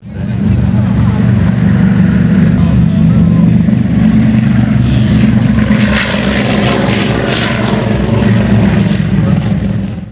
CONSOLIDATED B-32A DOMINATOR avec un moteur Wright Cyclone R-3350-23
Moteur de 18 cylindres sur 2 lignes refroidi par air avec une hélice de 4,02 m avec turbocompresseur